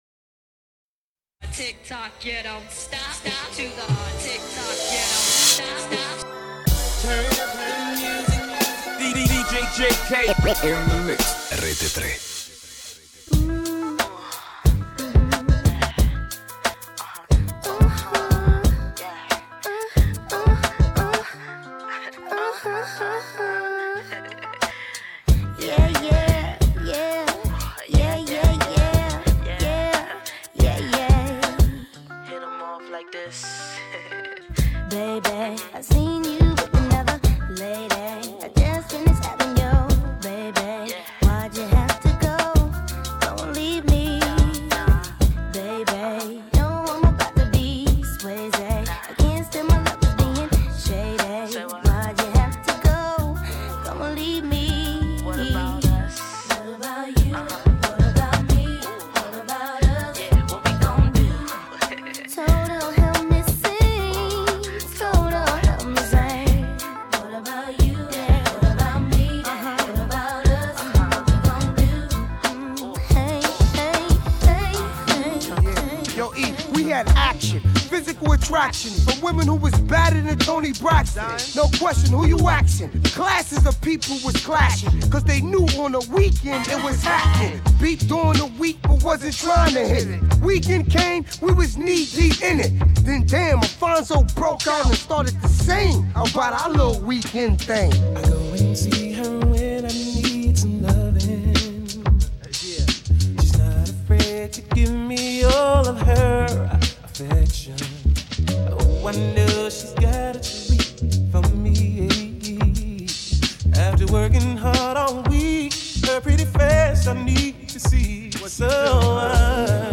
RAP / HIP-HOP BLACK